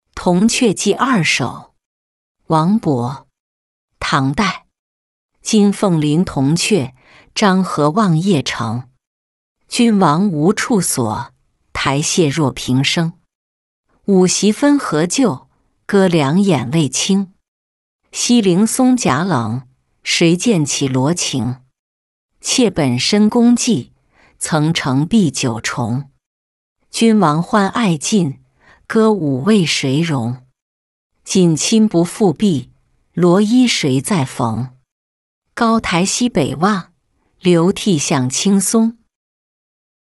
洛阳女儿行-音频朗读